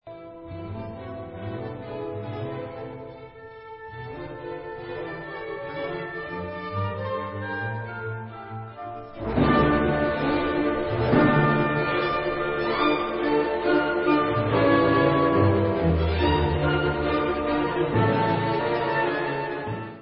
sledovat novinky v oddělení Klavírní koncerty
Klasika